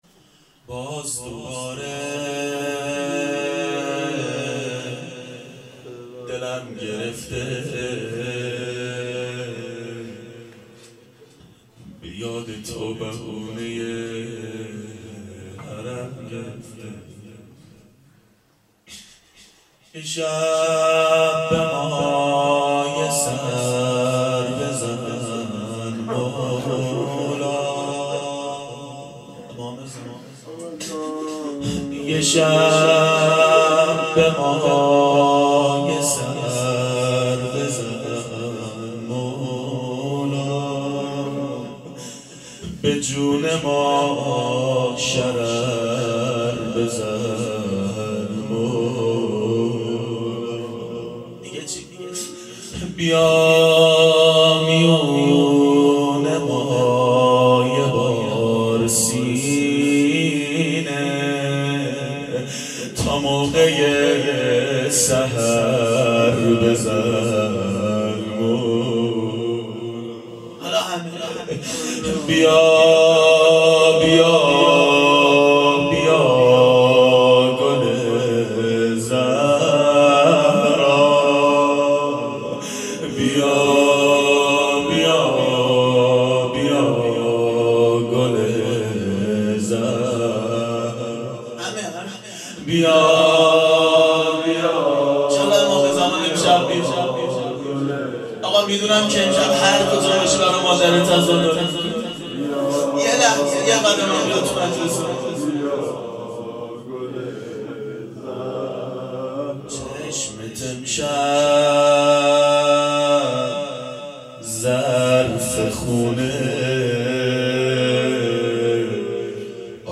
• شب شهادت حضرت زهرا سلام الله علیها 1389 هیئت عاشقان اباالفضل علیه السلام